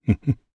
Clause_ice-Vox_Happy1_jp.wav